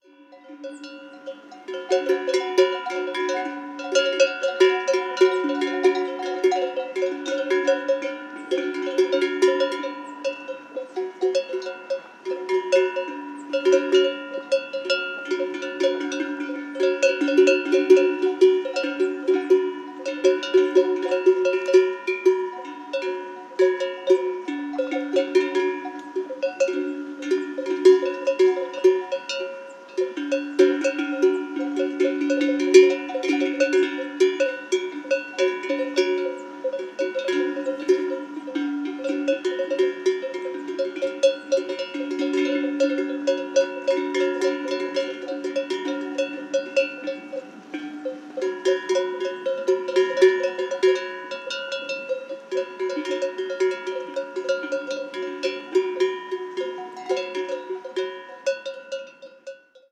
Ambiente de vacas en el prado
Sonidos: Animales Sonidos: Rural